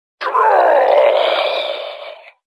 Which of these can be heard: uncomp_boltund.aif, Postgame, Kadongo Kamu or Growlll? Growlll